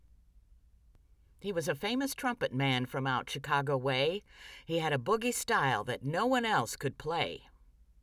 Your “Silent Sample” submission, is, in fact, not silent at all and contains a perfectly workable voice test.
It sounds a little like talking into a milk jug.
The overall noise volume is still too high and doesn’t match your earlier works, although now it’s much more calm and civilized and doesn’t have motor and fan noises in it.